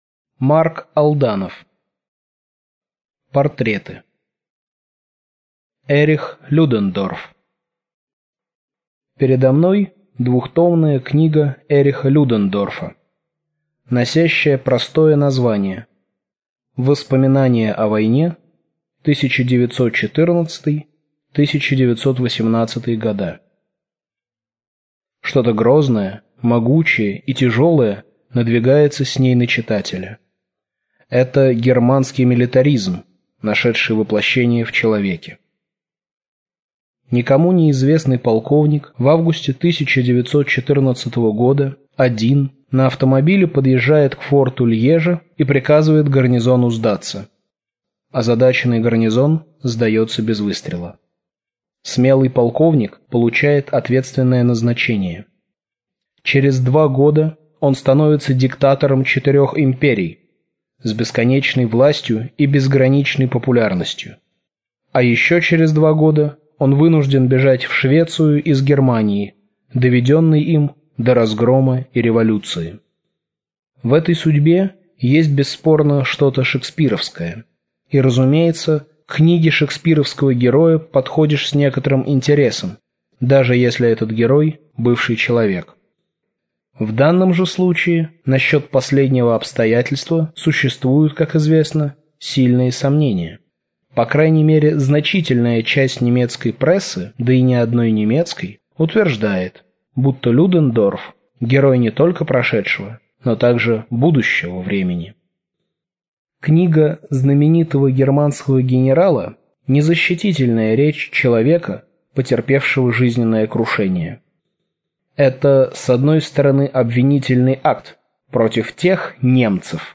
Аудиокнига Мольтке Младший. Ллойд Джордж. Эрих Людендорф. Ганди | Библиотека аудиокниг